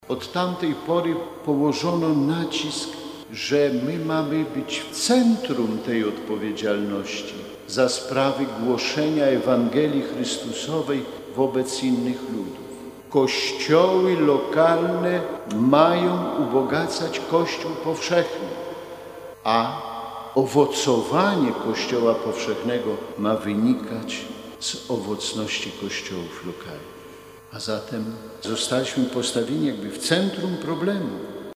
Centralnym punktem obchodów Nadzwyczajnego Miesiąca Misyjnego w diecezji warszawsko-praskiej była 24 października uroczysta msza św. w bazylice katedralnej św. Michała Archanioła i św. Floriana Męczennika.
W czasie mszy św. bp Kamiński zwrócił uwagę na aktualność, wydanego sto lat temu listu apostolskiego papieża Benedykta XV „Maximum Illud”.